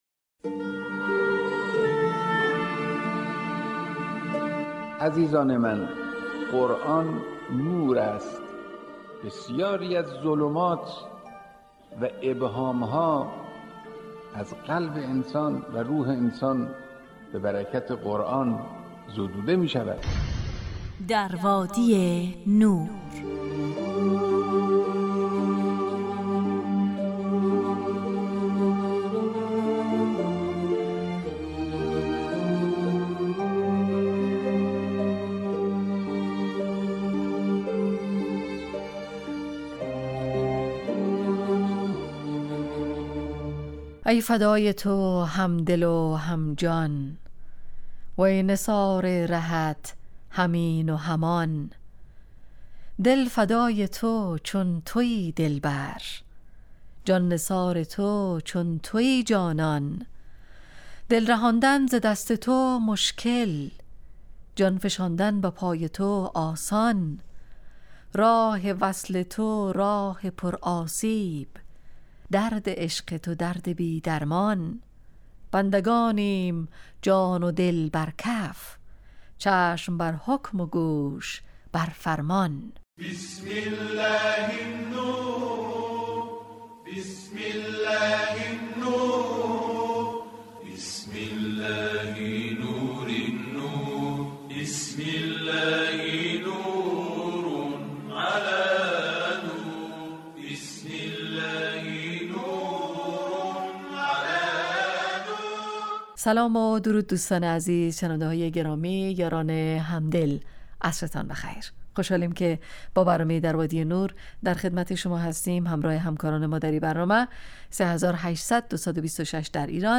برنامه ای 35 دقیقه ای با موضوعات قرآنی